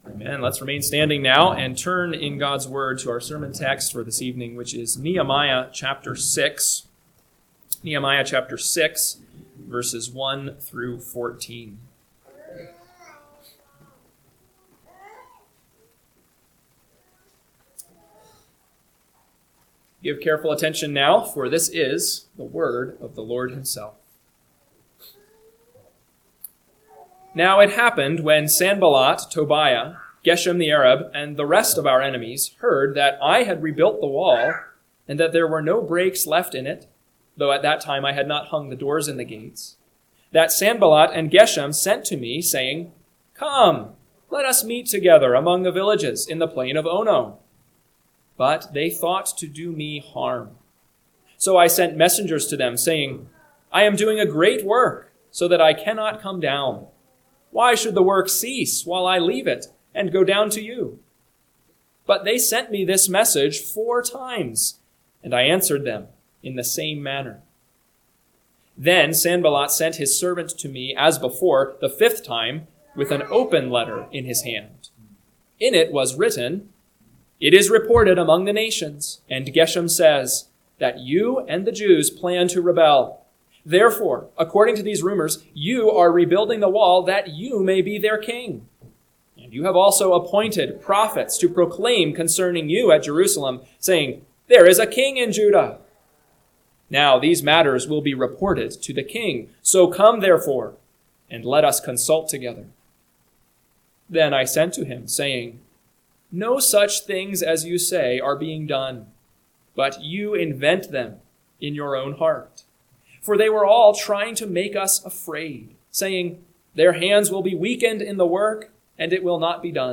PM Sermon – 9/7/2025 – Nehemiah 6:1-14 – Northwoods Sermons